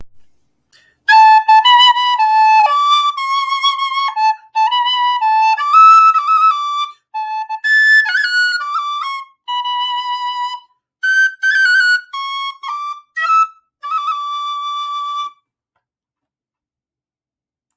flute.wav